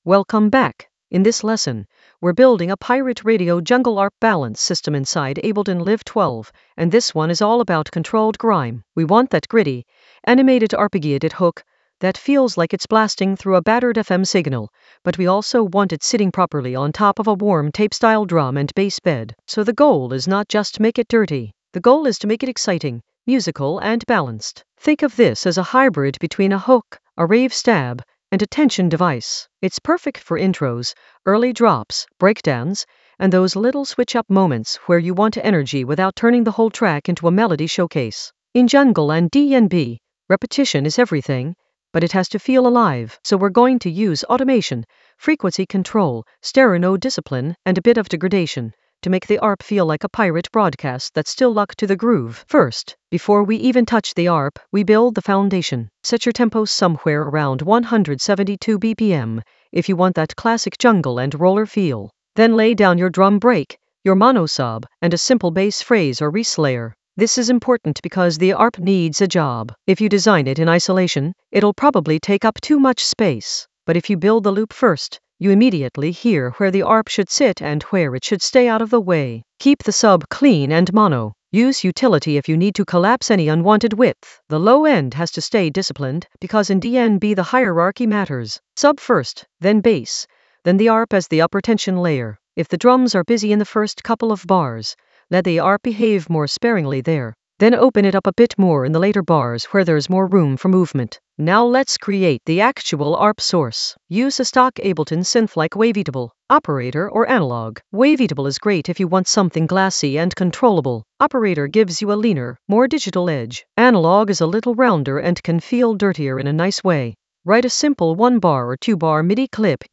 An AI-generated intermediate Ableton lesson focused on Pirate Radio jungle arp balance system for warm tape-style grit in Ableton Live 12 in the Automation area of drum and bass production.
Narrated lesson audio
The voice track includes the tutorial plus extra teacher commentary.